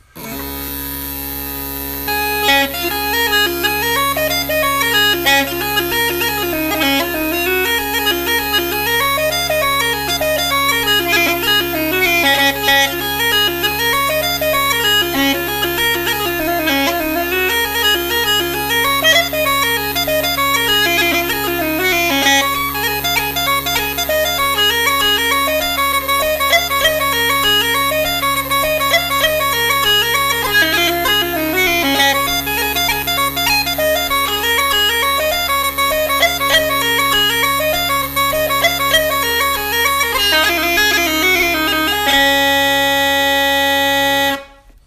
Devaney’s Goat – Sean-nós & Set Dance